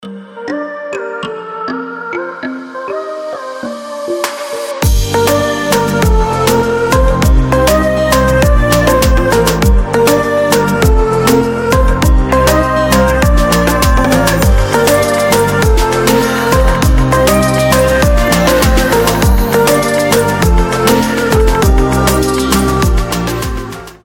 мелодичные
chillout
теплые
Согревающая chill house мелодия